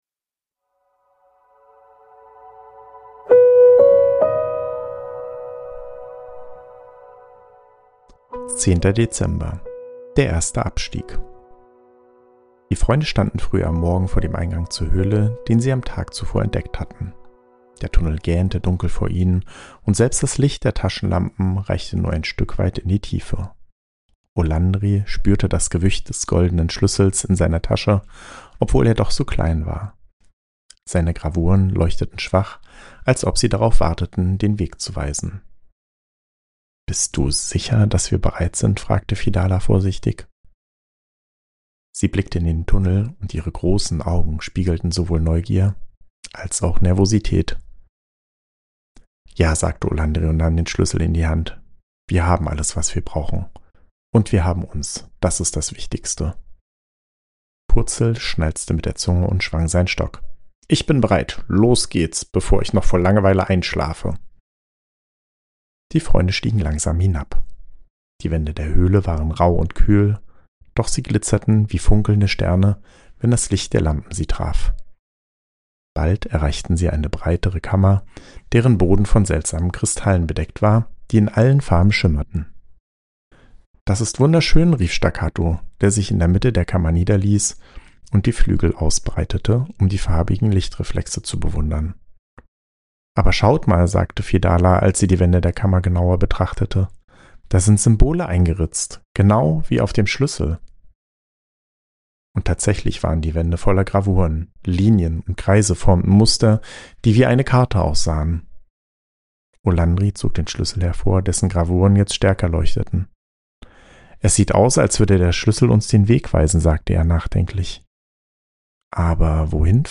Ruhige Adventsgeschichten über Freundschaft, Mut und Zusammenhalt